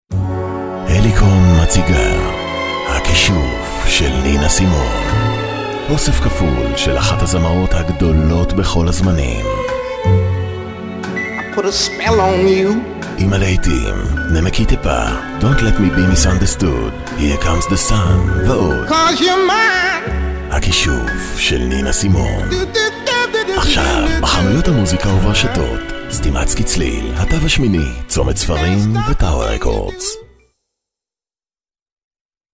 Very experienced Hebrew Voice over artist from Israel. own studio. provide production facilities.
Sprechprobe: eLearning (Muttersprache):